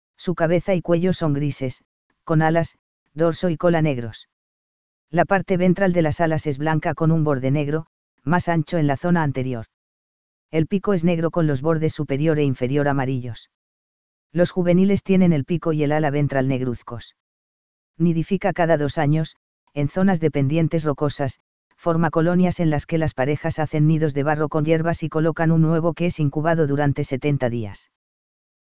Thalassarche (Diomedea) chrysostoma - Albatros cabeza gris
Albatroscabezagris.mp3